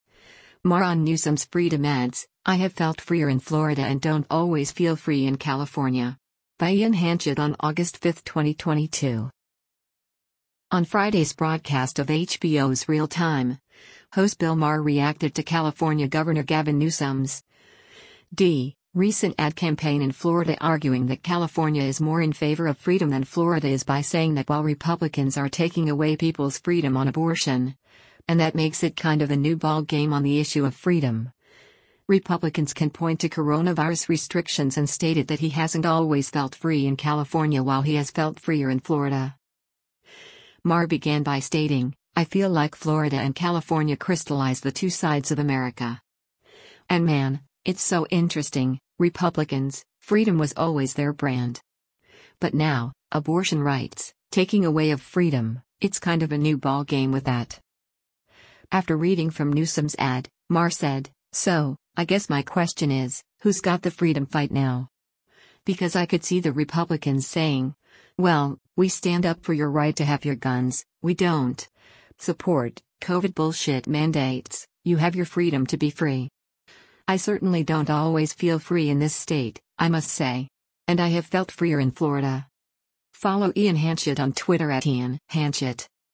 On Friday’s broadcast of HBO’s “Real Time,” host Bill Maher reacted to California Gov. Gavin Newsom’s (D) recent ad campaign in Florida arguing that California is more in favor of freedom than Florida is by saying that while Republicans are “taking away” people’s freedom on abortion, and that makes it “kind of a new ball game” on the issue of freedom, Republicans can point to coronavirus restrictions and stated that he hasn’t always felt free in California while he has “felt freer in Florida.”